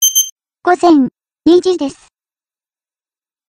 音声で時報をお知らせします。